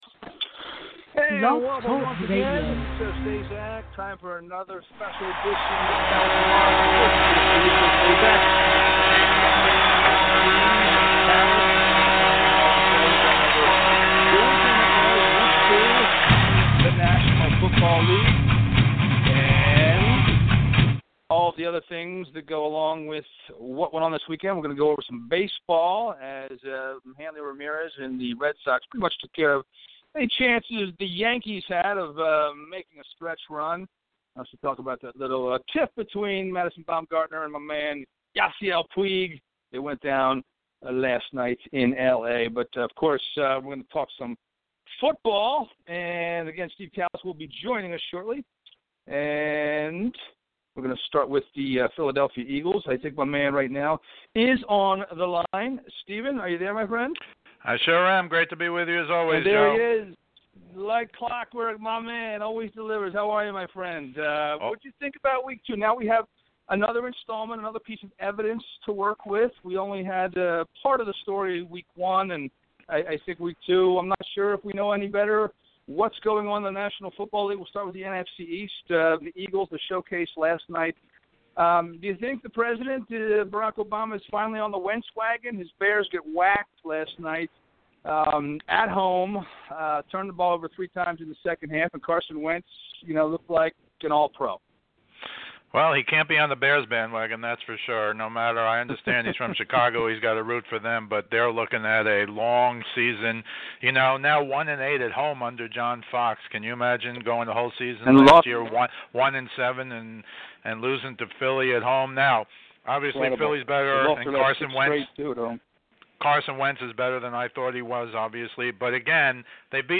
Call in show covering all things sport